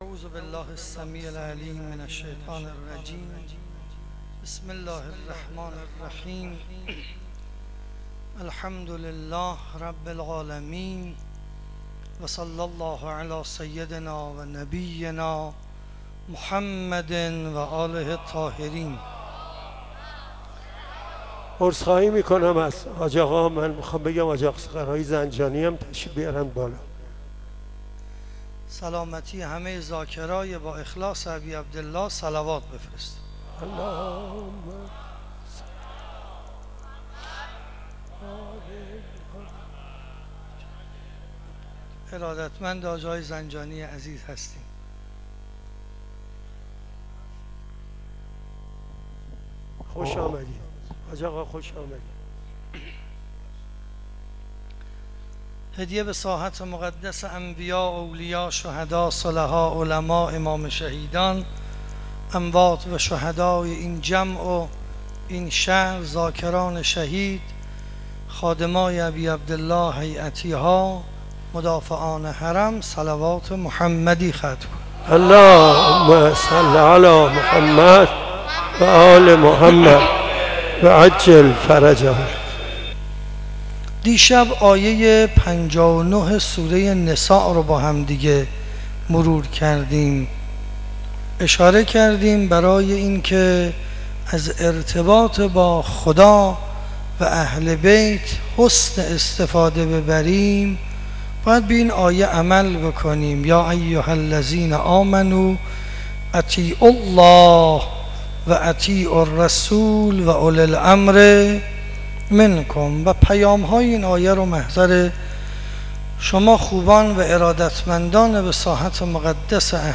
شب 12 رمضان 97- مسجد حضرت قاسم ابن الحسن علیهما السلام